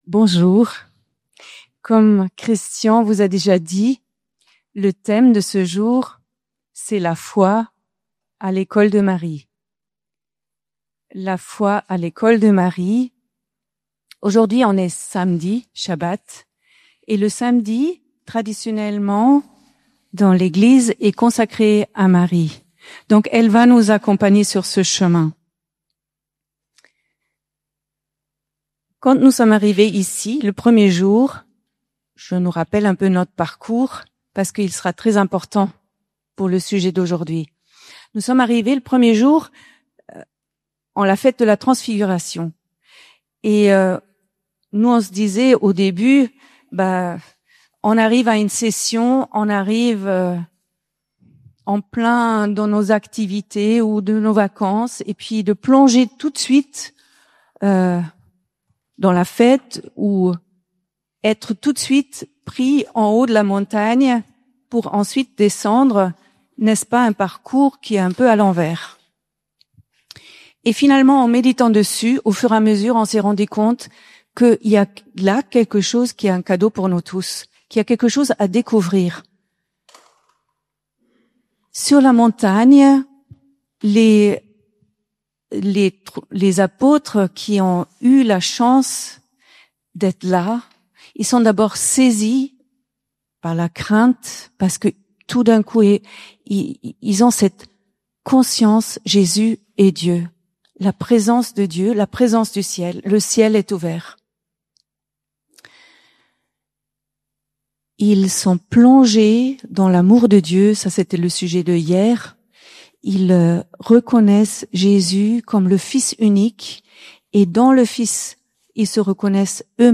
Nouan-le-Fuzelier, Festival des familles